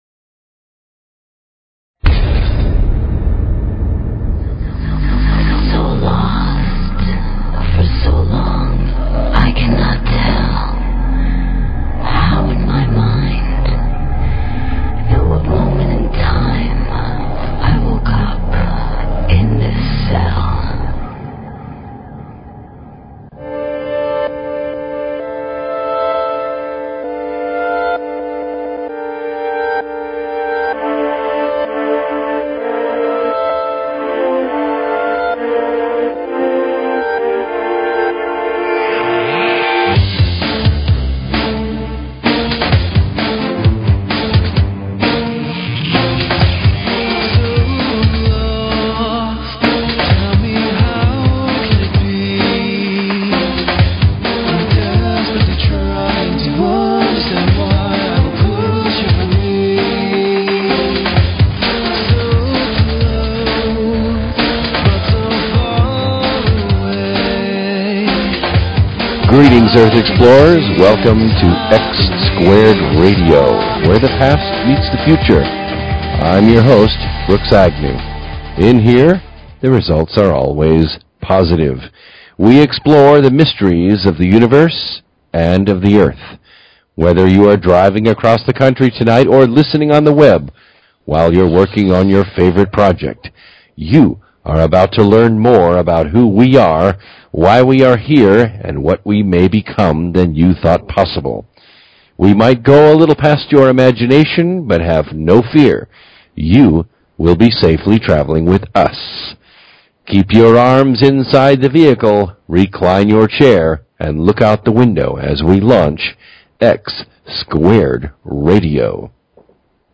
Talk Show Episode, Audio Podcast, X-Squared_Radio and Courtesy of BBS Radio on , show guests , about , categorized as
Two callers help us understand the phenomenon and deeper subjects. Also, we discussed the events of 2012 coming up and how to get prepared.